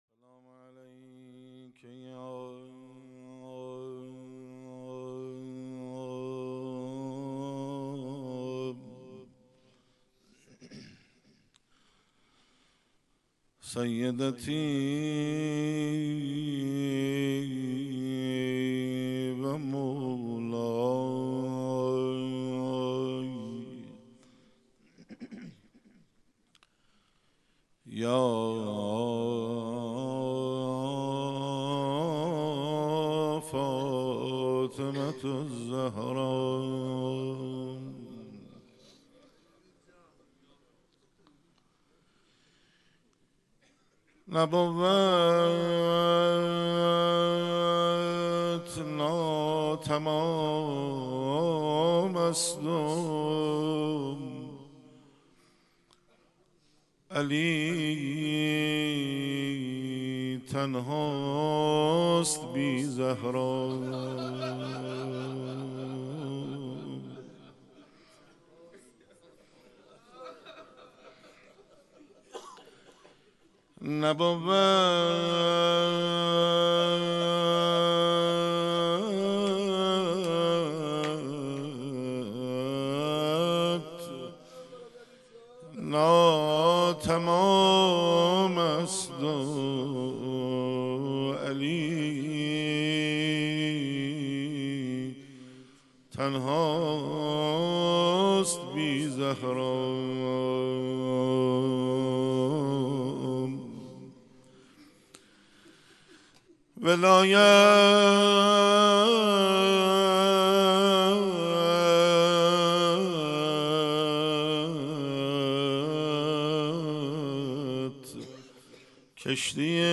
مداحی
حسینیه انصارالحسین علیه السلام